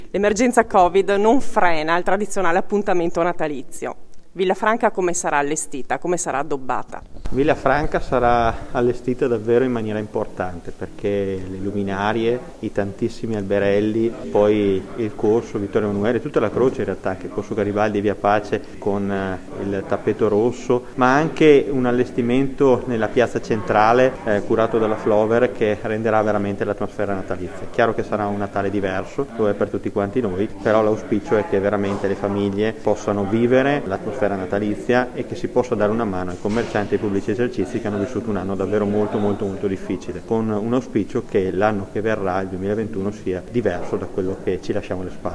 Mai come quest’anno è importante dare un segnale ai cittadini, come ha sottolineato l’assessore alle Manifestazioni Luca Zamperini